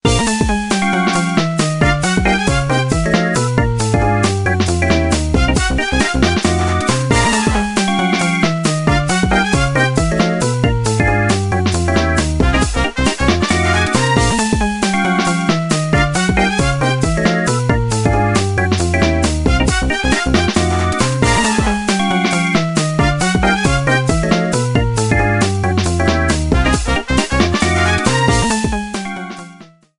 minigame theme rearranged